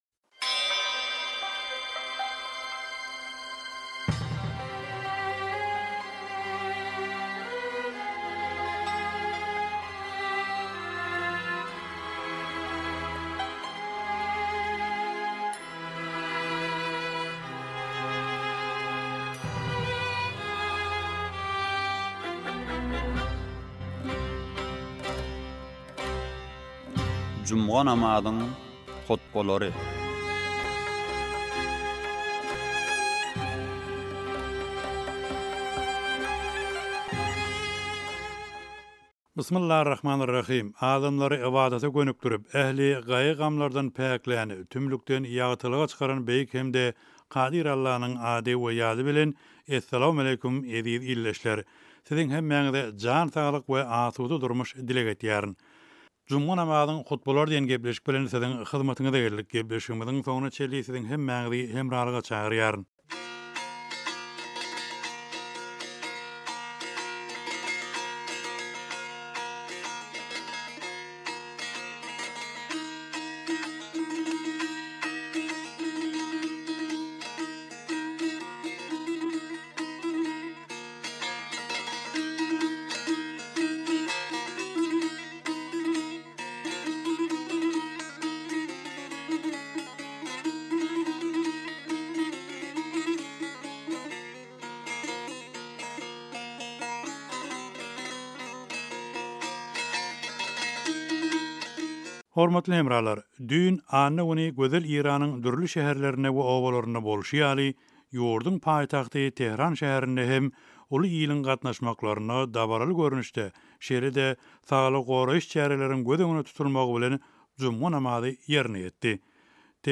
juma namazyň hutbalary
Tehraniň juma namazy